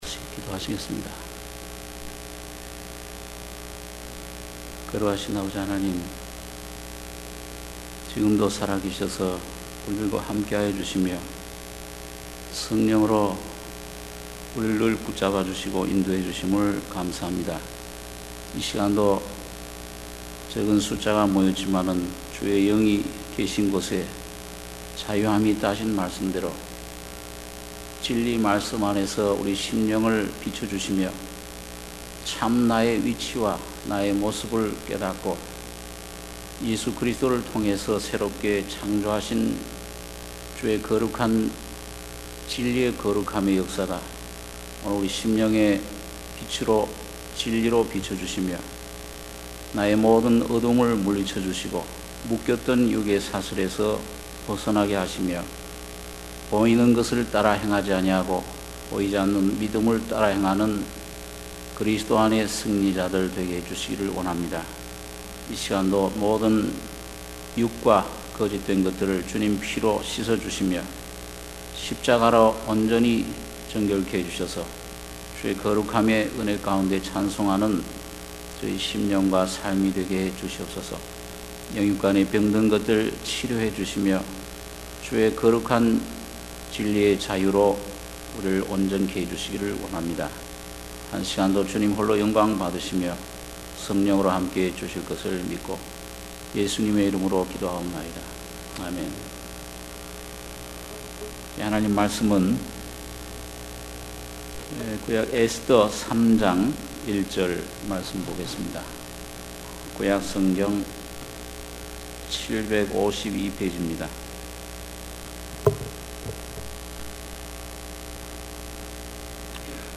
수요예배 - 에스더 3장 1-11절